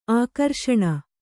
♪ ākarṣaṇa